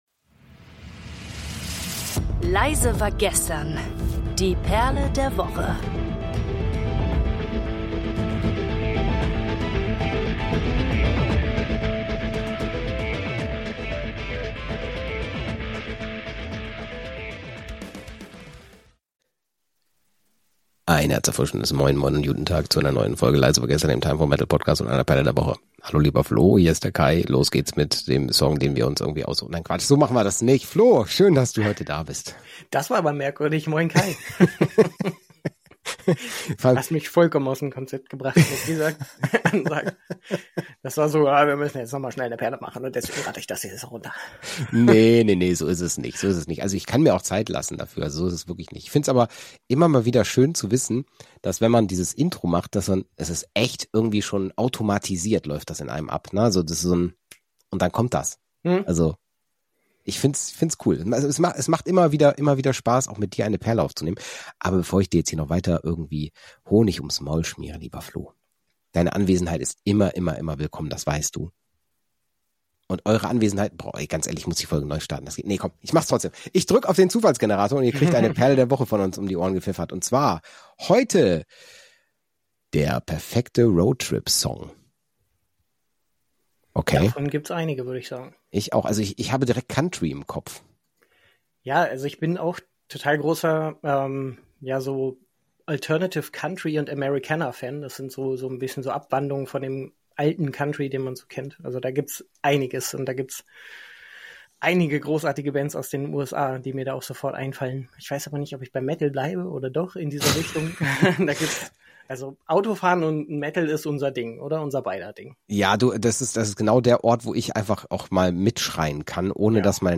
Mit viel Humor, nostalgischen Anekdoten und einem Schuss Genre-Vielfalt sprechen die beiden Hosts über Klassiker, neue Entdeckungen und ihre ganz persönlichen Favoriten für den perfekten Soundtrack hinterm Steuer.
✅ Persönliche Verbindungen, kulturelle Unterschiede & viel Gelächter